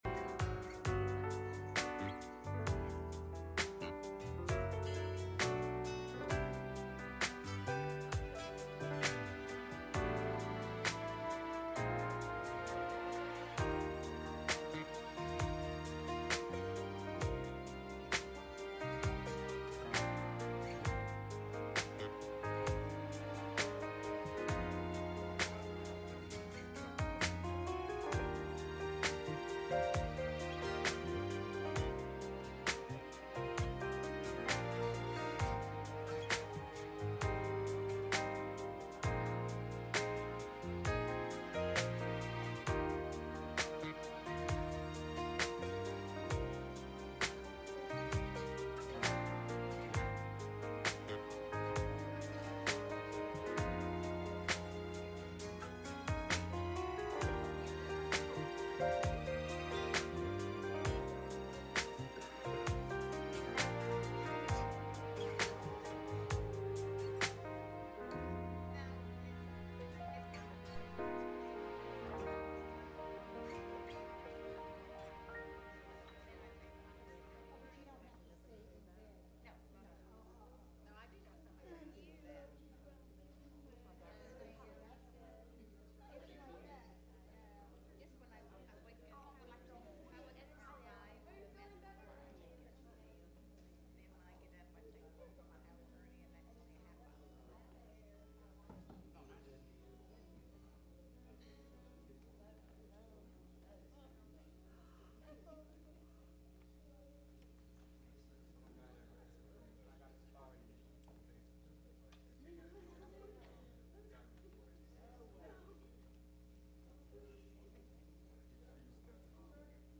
Wednesday Night Service
Message Service Type: Midweek Meeting https